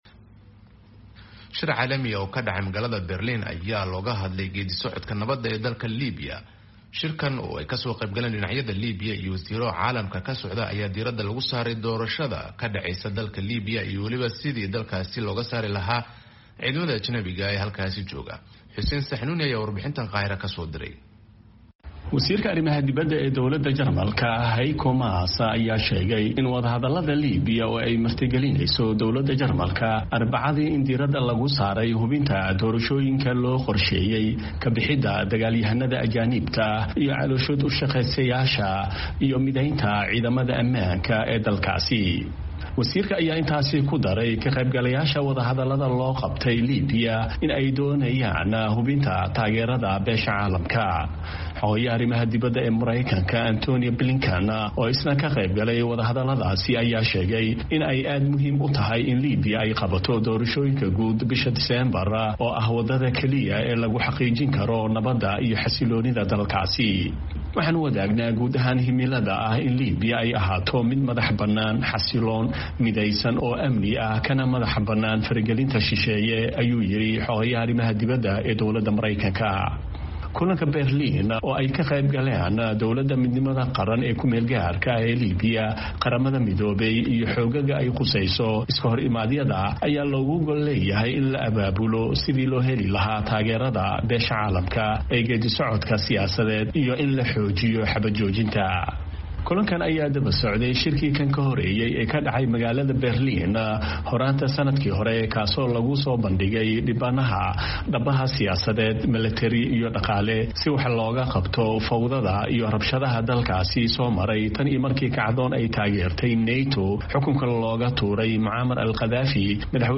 CAIRO —